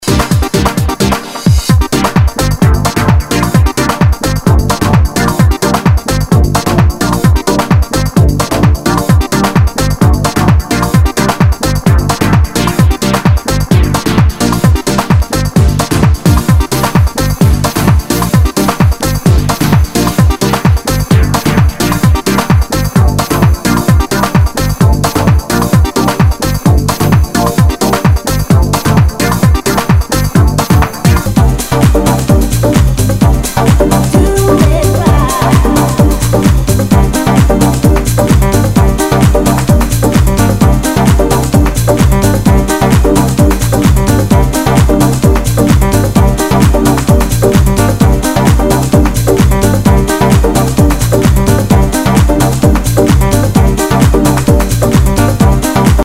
HOUSE/TECHNO/ELECTRO
ナイス！テック・ハウス！